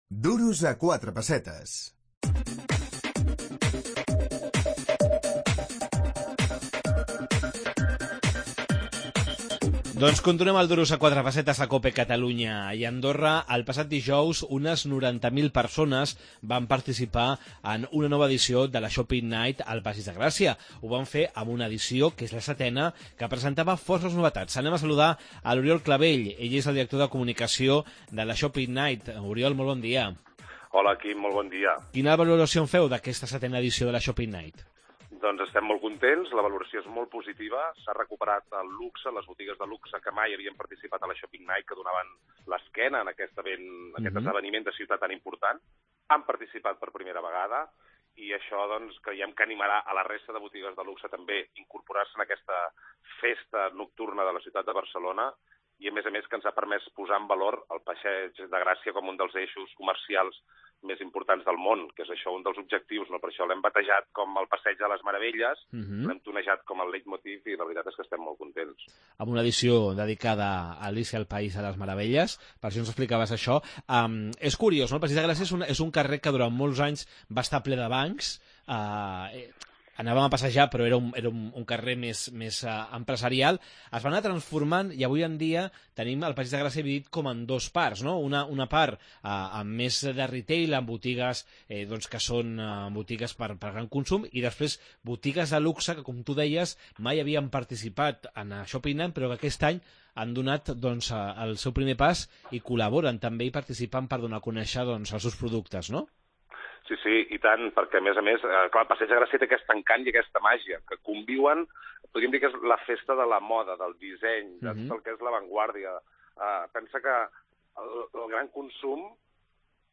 Unes 90 mil persones assisteixen a la Shopping Night de Barcelona. Entrevista